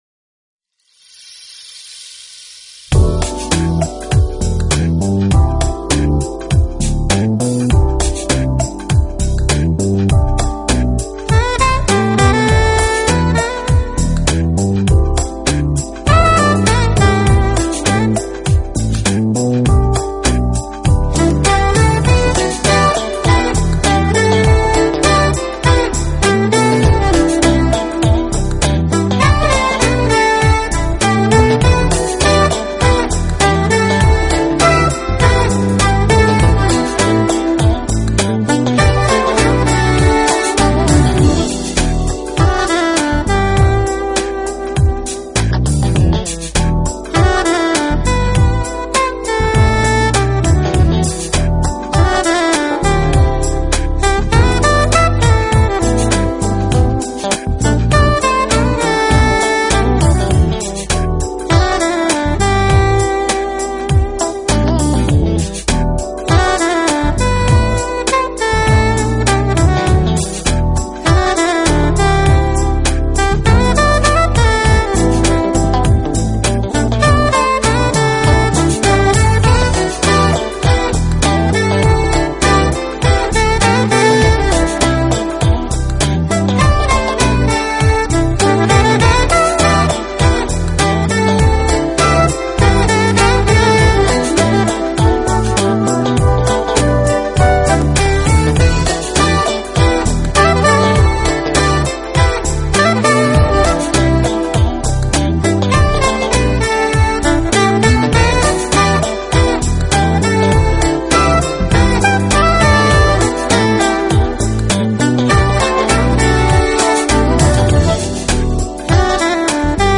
音乐类型：Smooth Jazz
抒情流畅而悦耳的旋律，还有他那充满深情的蓝调律动，给人带来的是震撼。
saxophone,  keyboards